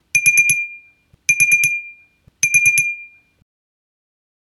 Stressed ding ding
bell ding ring ting sound effect free sound royalty free Sound Effects